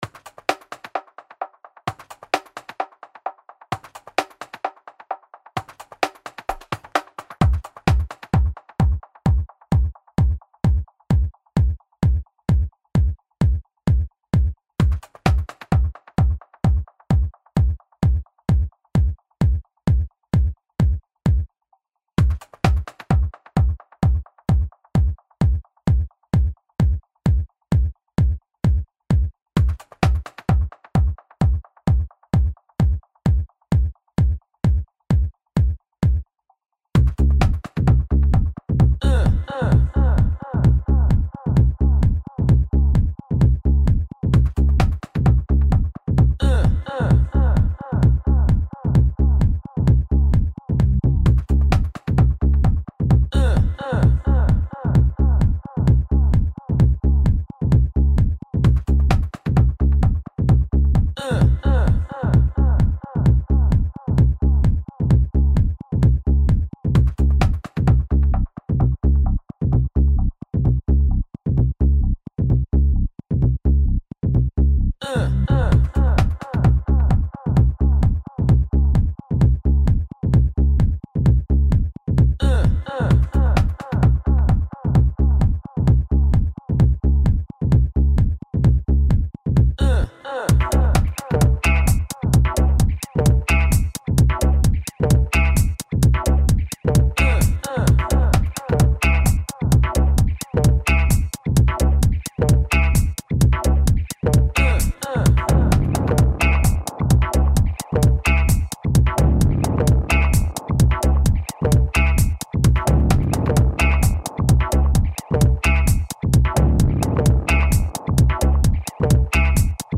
dance/electronic